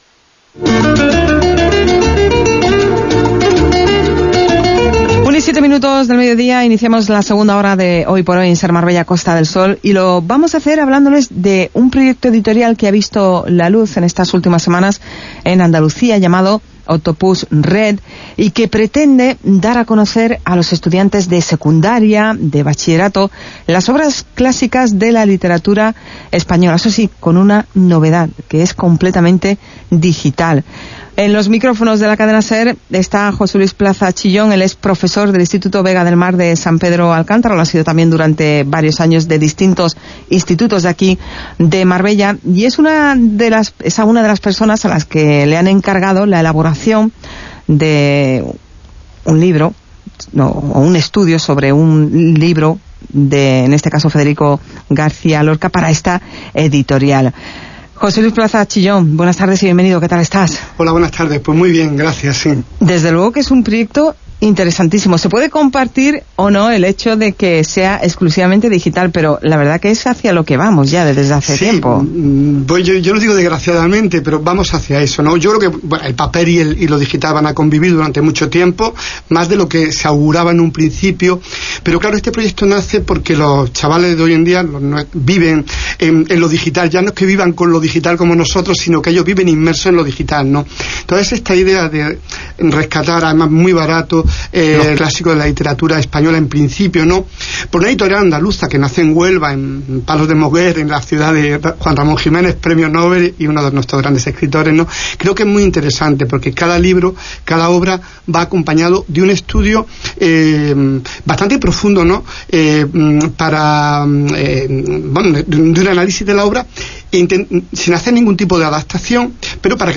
Entrevista-Cadena-Ser-2.wav